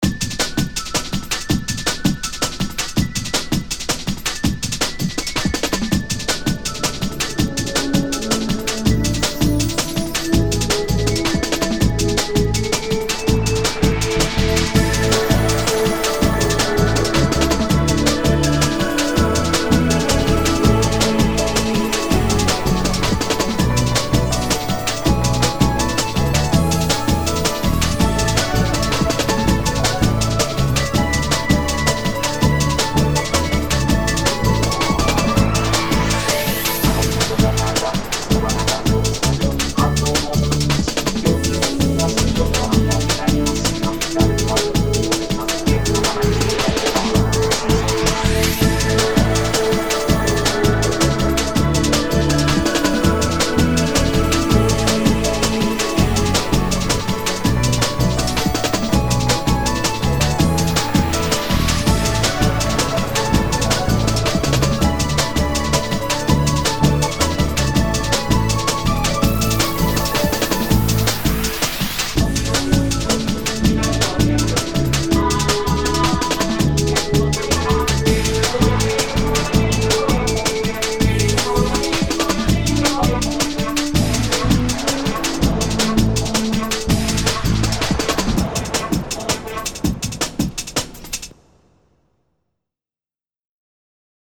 ボコーダートラック入りと、無し・・・。